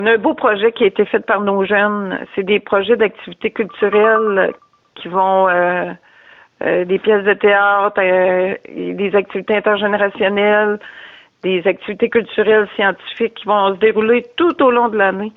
En entrevue, la mairesse, Ginette Deshaies, a mentionné que les jeunes ont été très actifs dernièrement.